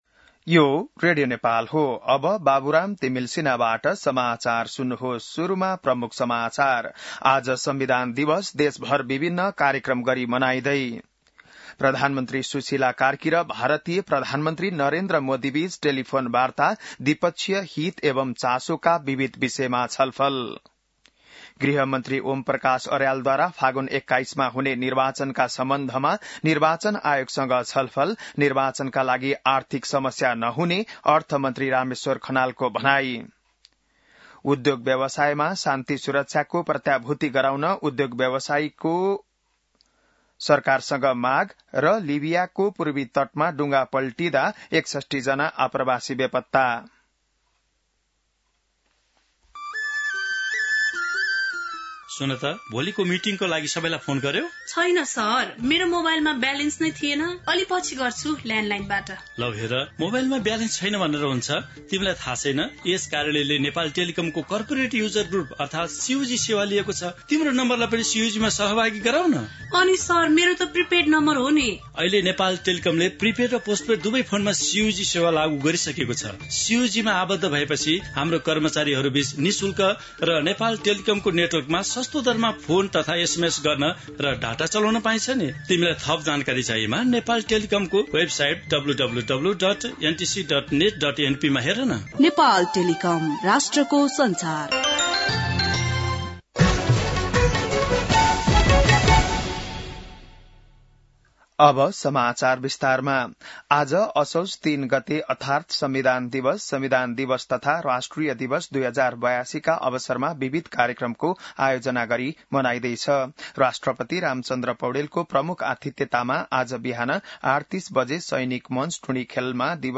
An online outlet of Nepal's national radio broadcaster
बिहान ७ बजेको नेपाली समाचार : ३ असोज , २०८२